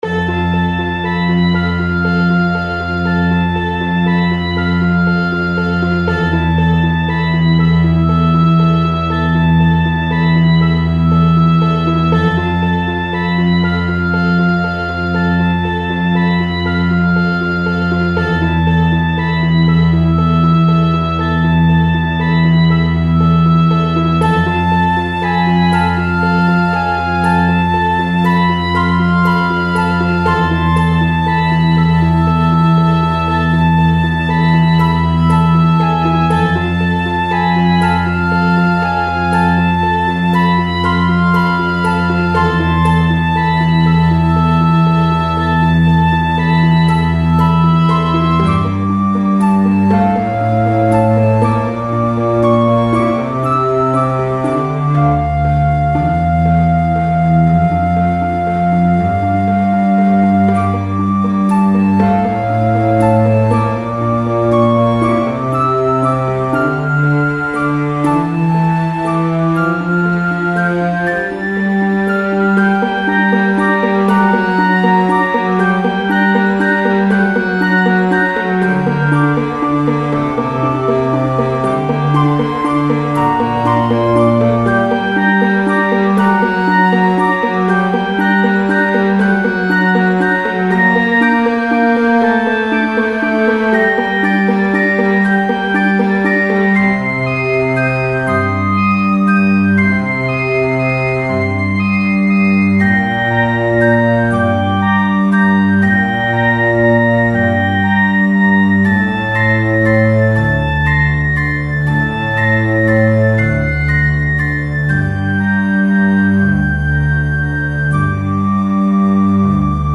This is a great way of getting quality sounding instruments for free.
To make this song, I had to write one track at a time out to wav then composite them in a seperate program.
I also realized that the instruments I used didn’t have sounds for some of the notes I wrote so part of the song doesn’t even play.